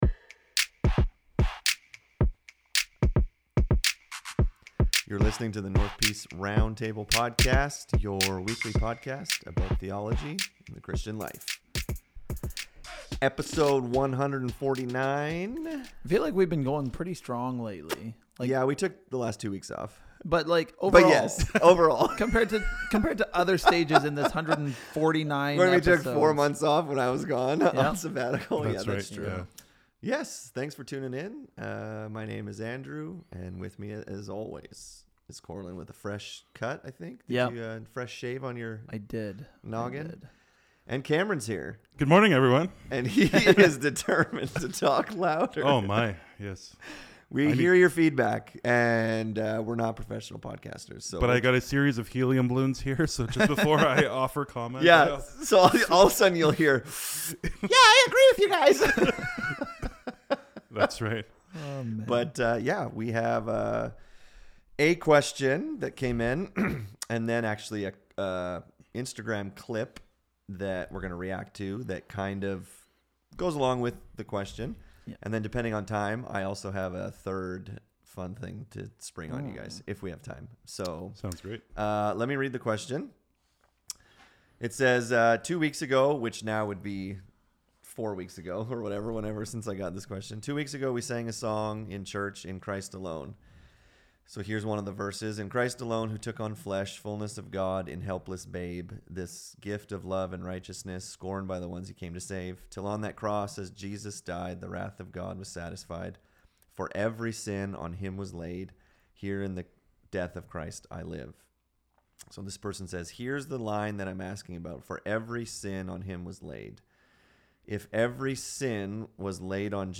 In this episode the guys talk about the atonement. Who exactly did Jesus die for on the cross? The guys also react to a sermon clip about universalism.